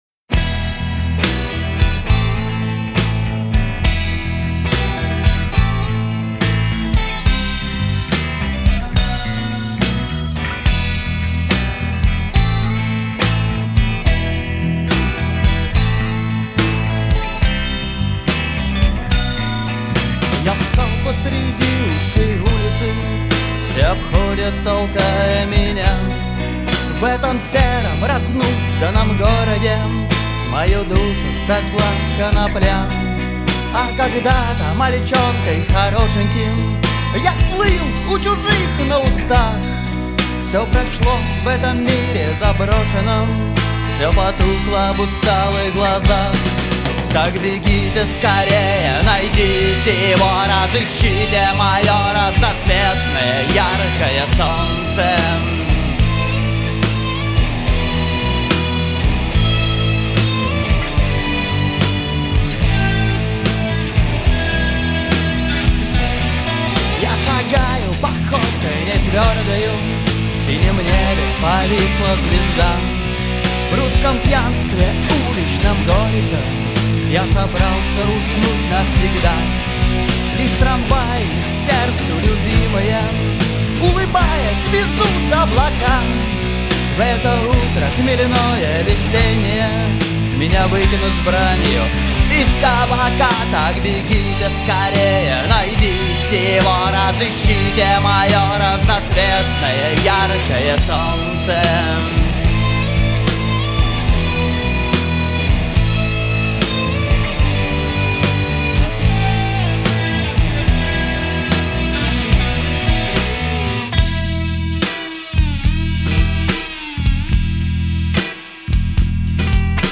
.: Наши демо-записи :.